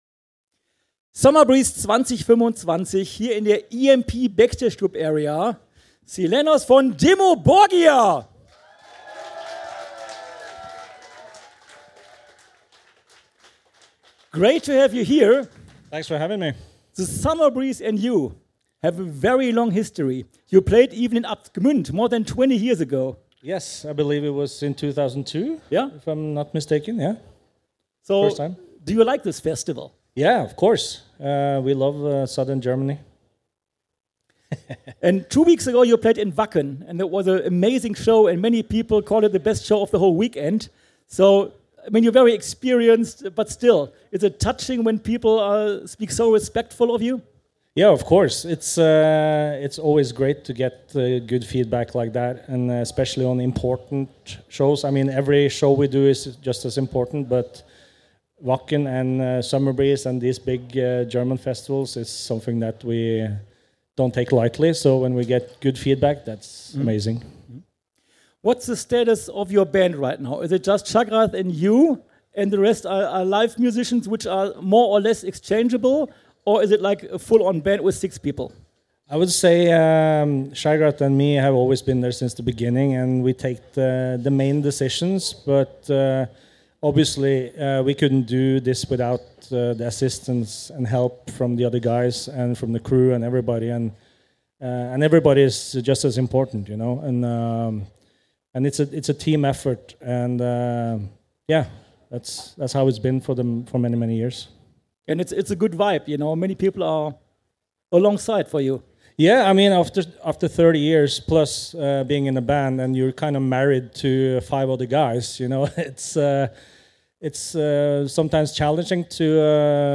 Summer Breeze 2025 Special - Dimmu Borgir - Live aus der EMP Backstage Club Area